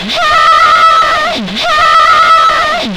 DISTOSCREA-R.wav